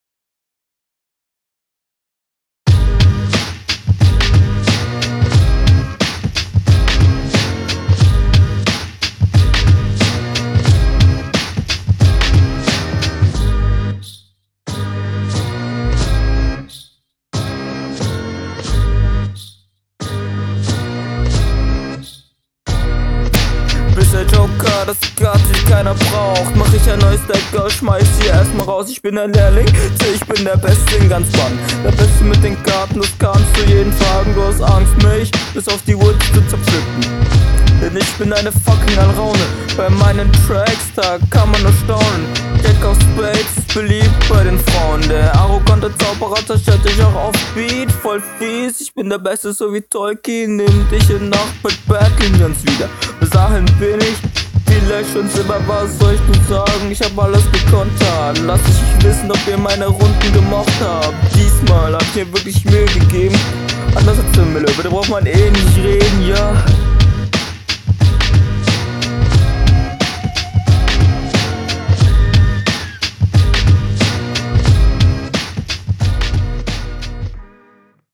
Leider versteht man hier auch wieder vieles nicht.